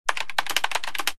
Keyboard7.wav